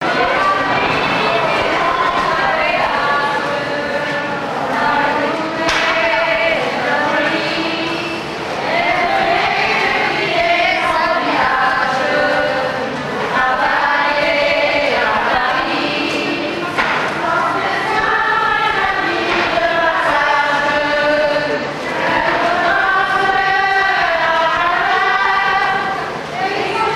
Usine de conserves de sardines Amieux
Genre strophique
Chansons traditionnelles
Pièce musicale inédite